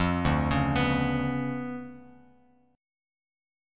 DmM13 chord